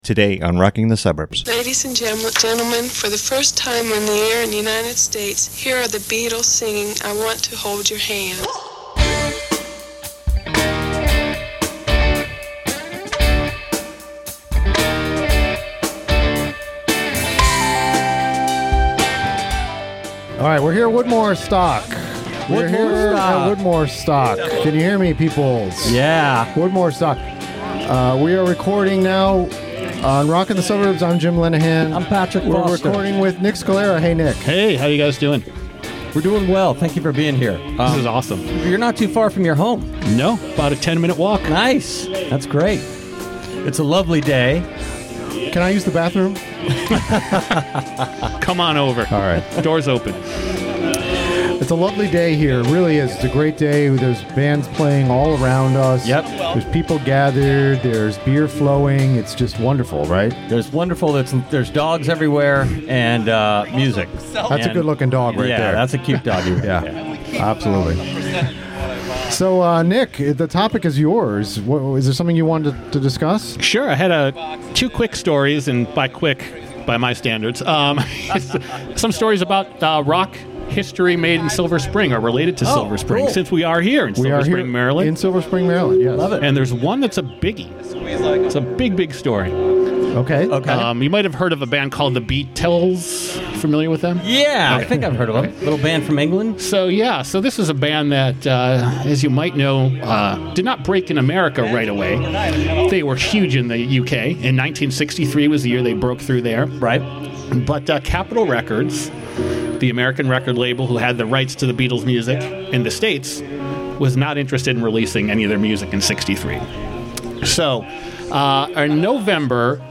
Live from Woodmoorstock